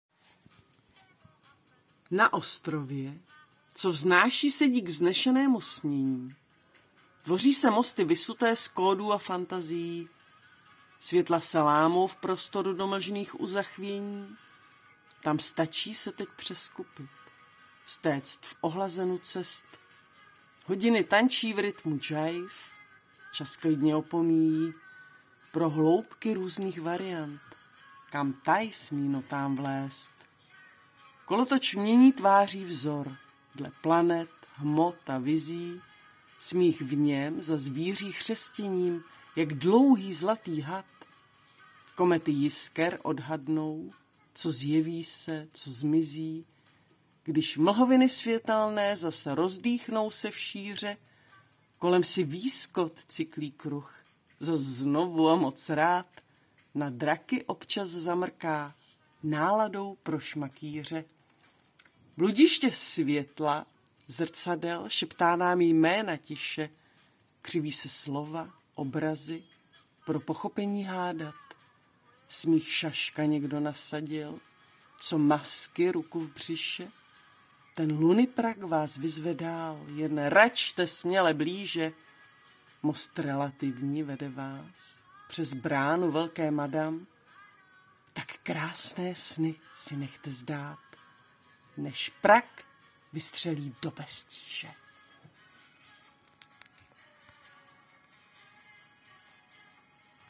Fantasktně parádně hravá, včetně Tvého přednesu! :)**